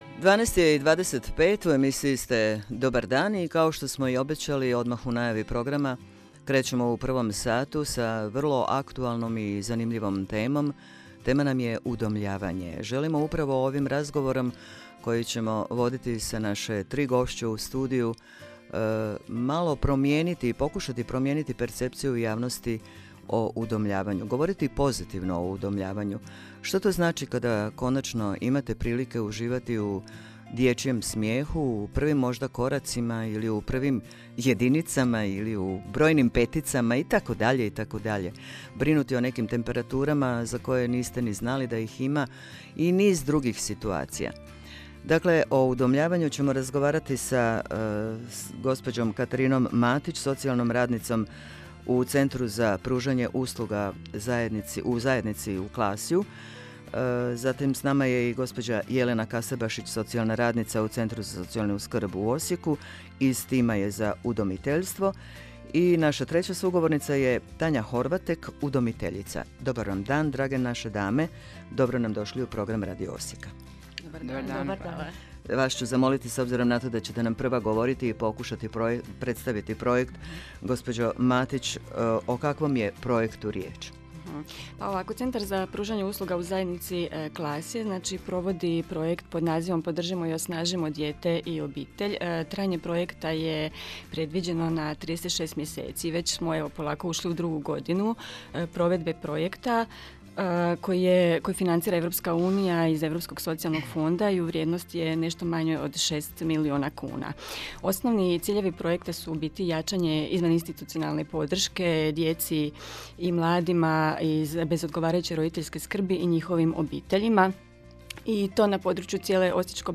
S obzirom na trenutnu epidemiološku situaciju, tribina „Kako postati udomitelj“, umjesto u živo, organizirana je u obliku radijske emisije. Gostovali smo u emisiji „Dobar dan“ HRT-Radio Osijeka 10.12.2020.g. s ciljem promicanja udomiteljstva za djecu.